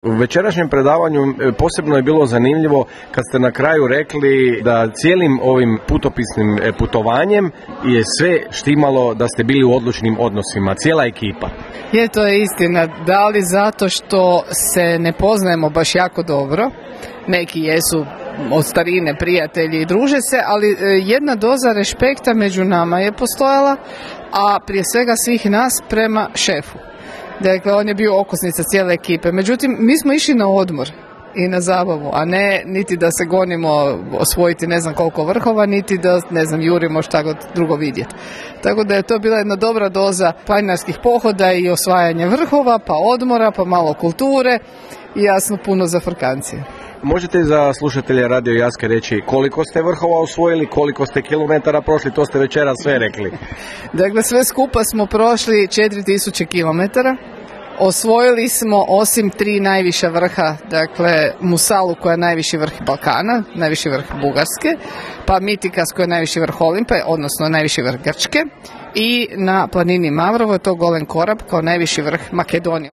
VRH-RAZGOVOR.m4a